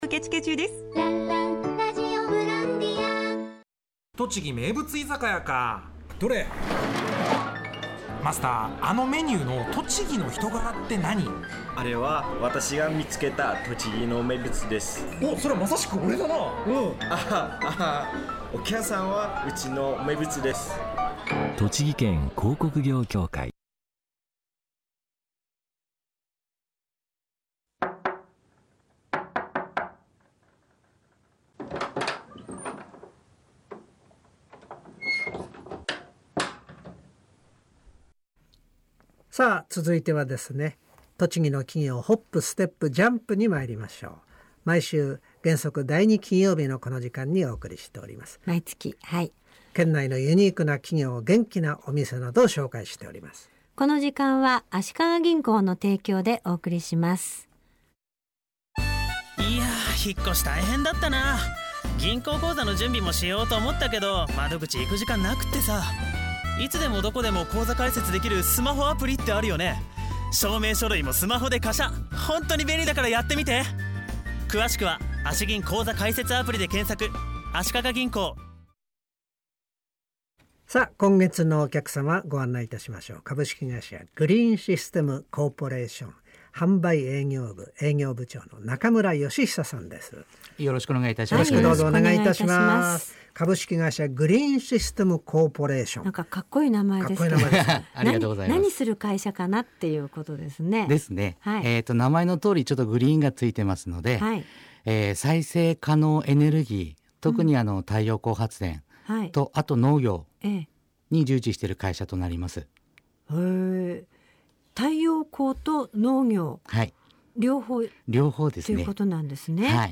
CRT栃木放送「まるきん大行進！」に出演！